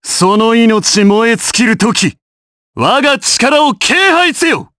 Shakmeh-Vox_Skill7_jp.wav